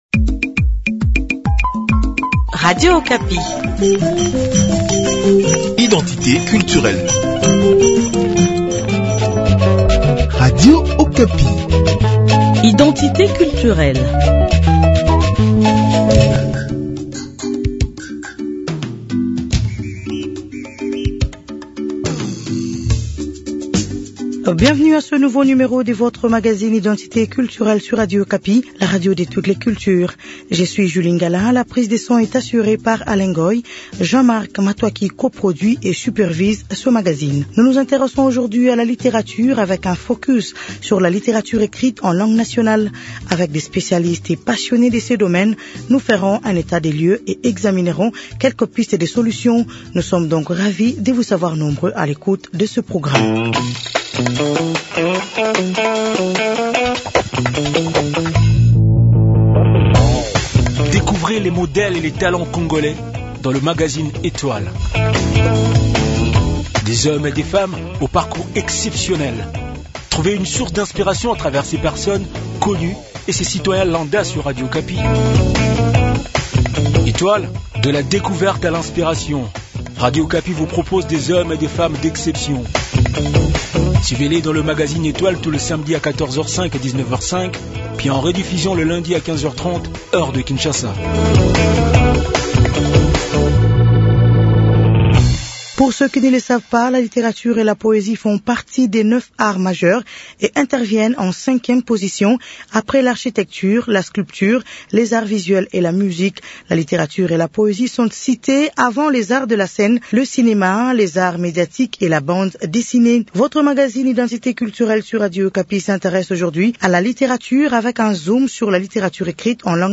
La littérature en langues locales, existe-t-elle en République Démocratique du Congo ? Nous faisons un état de lieu du cinquième art avec ses spécialistes et passionnés, tout en relevant certaines initiatives qui sont mises en place pour sa promotion.